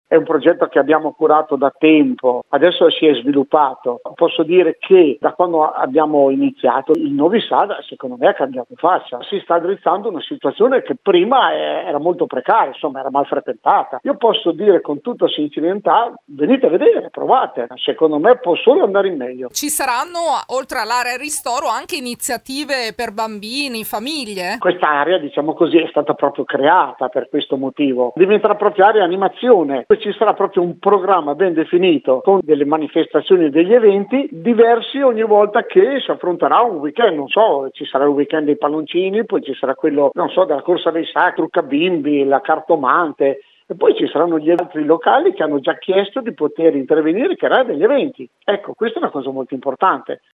Qui sotto l’intervista